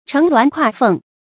乘鸾跨凤 chéng luán kuà fèng 成语解释 乘鸾：求得佳偶。